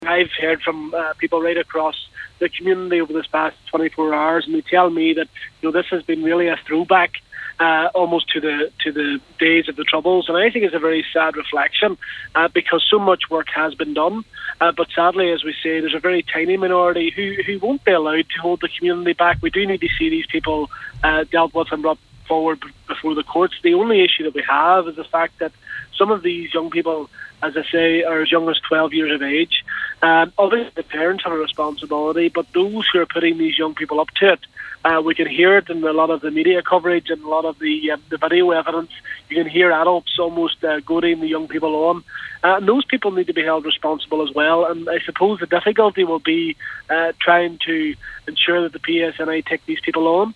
MLA Gary Middleton says a lot of young people were involved and believes there’s an onus on parents to know where their children are: